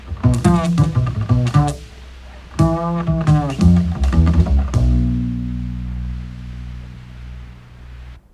acoustic.mp3